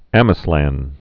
(ămĭ-slăn)